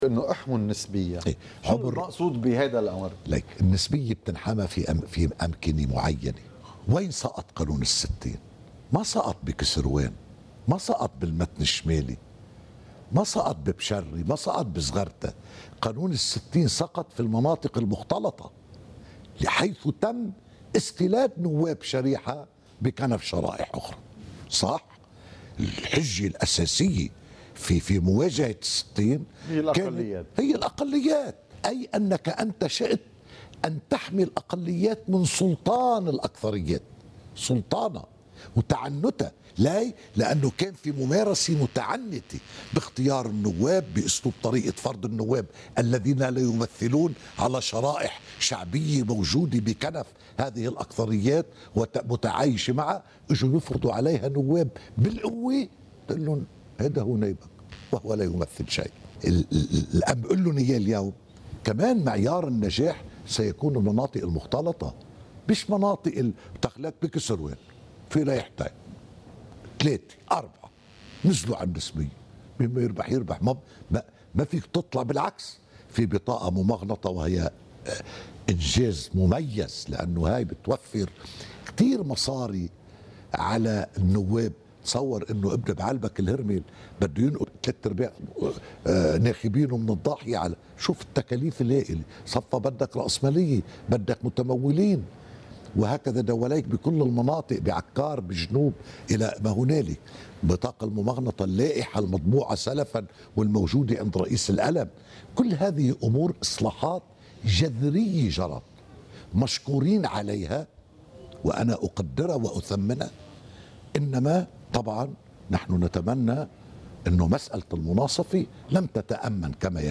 مقتطف من حديث رئيس مجلس النواب الأسبق ايلي الفرزلي لقناة الـ”mtv”: